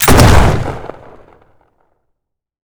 gun_shotgun_shot_03.wav